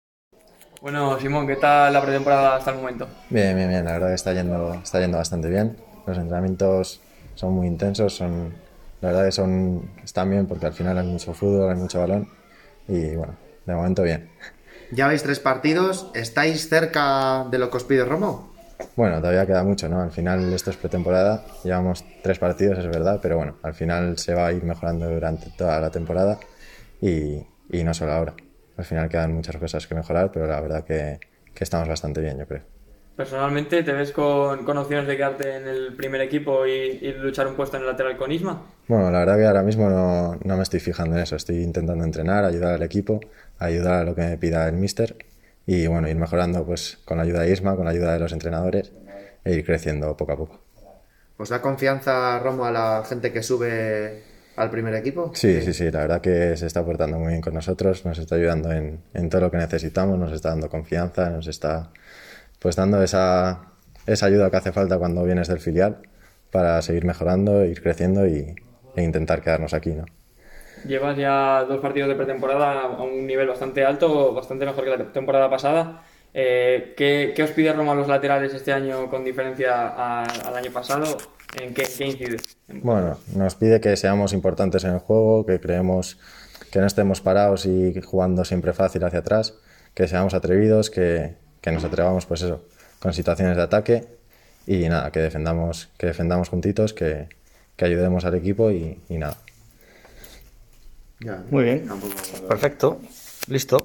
ha comparecido ante los medios de comunicaci�n tras el entrenameinto celebrado hoy en las Instalaciones Nando Yosu de la Albericia.